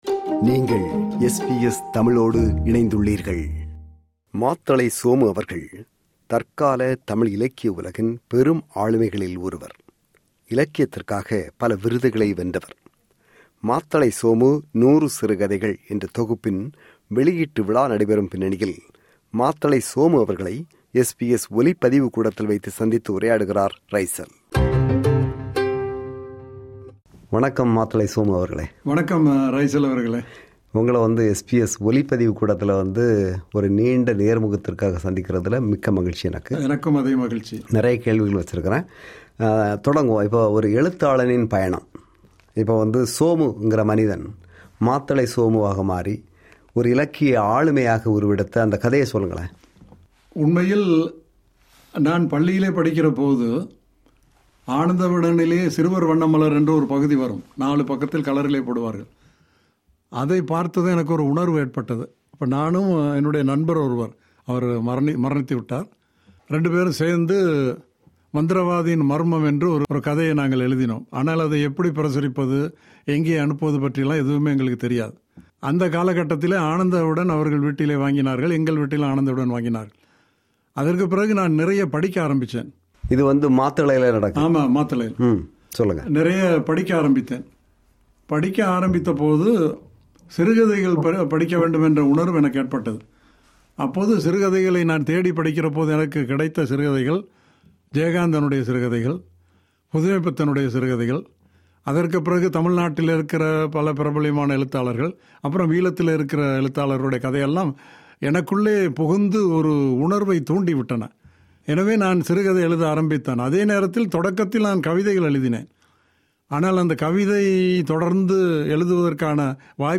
நேர்முகம் பாகம்: 1